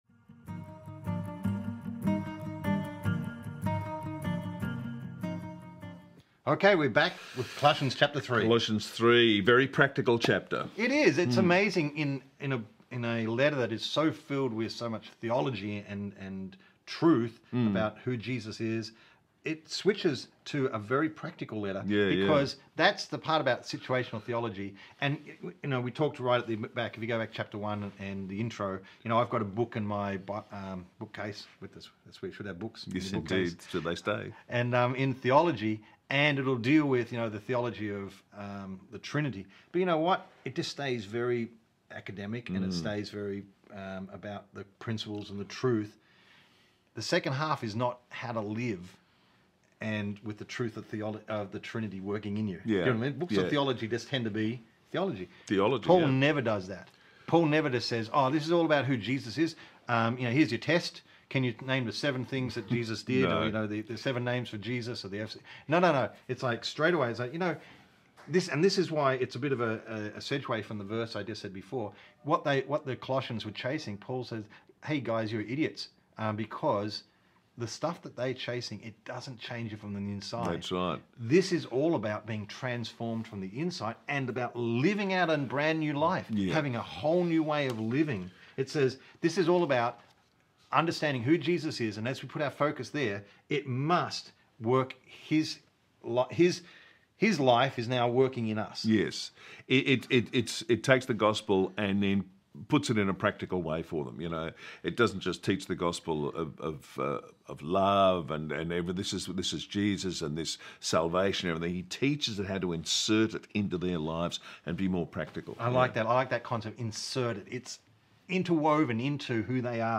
Bible readings from the Twentieth Century New Testament with Bible Teacher